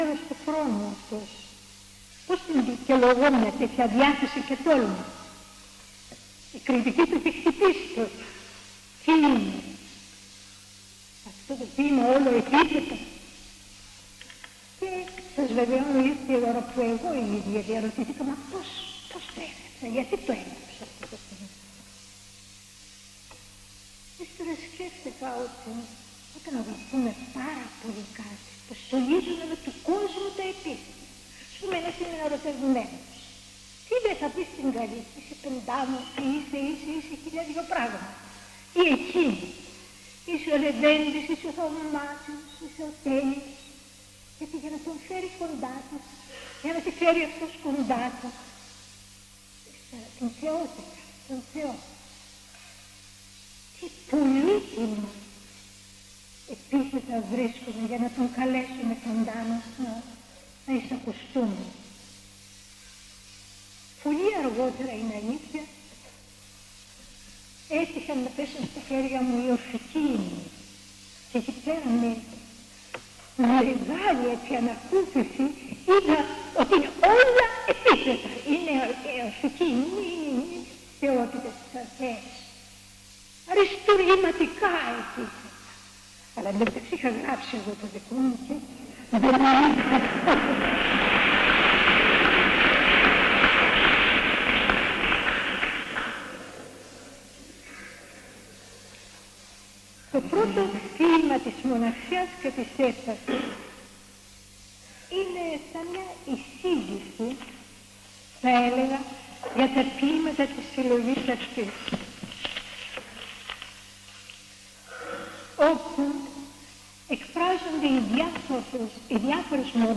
Εξειδίκευση τύπου : Εκδήλωση
Περιγραφή: Εισαγωγική Ομιλία του Γ. Π. Σαββίδη
Περίληψη: Η Ζωή Καρέλλη διαβάζει ποιήματά της